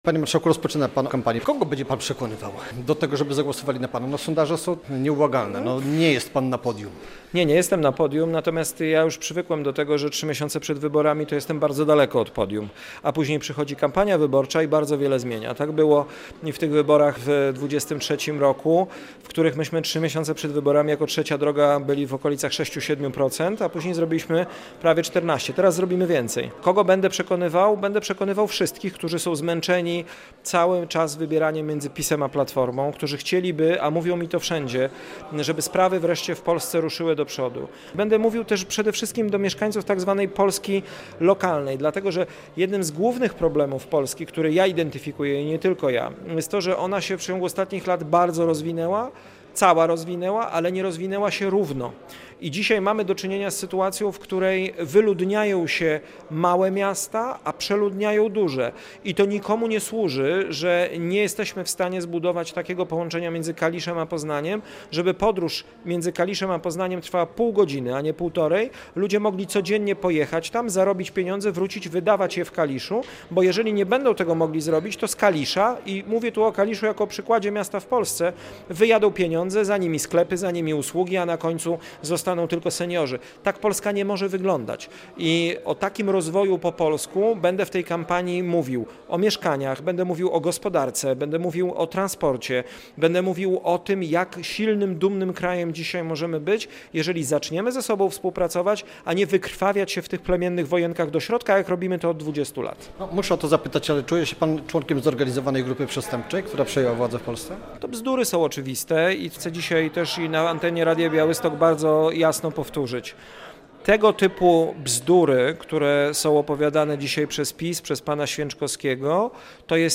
Radio Białystok | Gość | Szymon Hołownia - marszałek Sejmu, kandydat na prezydenta RP